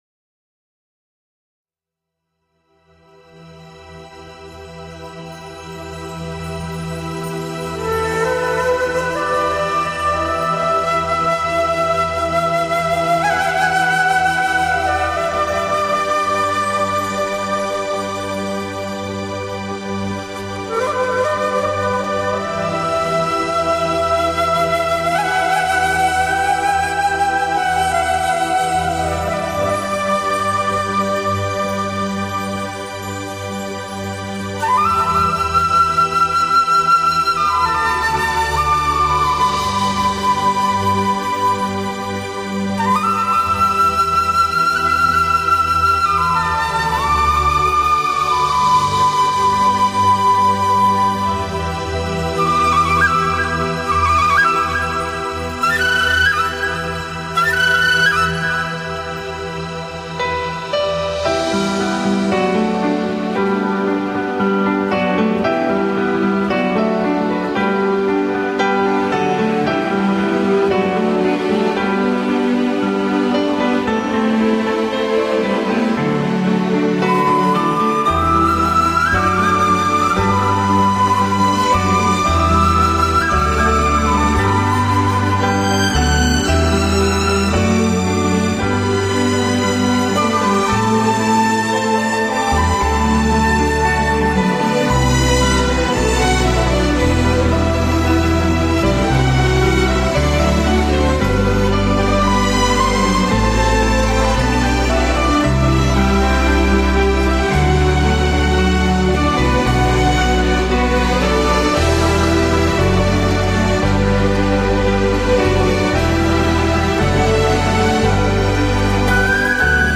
唯美的管弦乐缓缓带出钢琴无比夏梦的心情旋律，仿佛置身于灵异世界的另一边...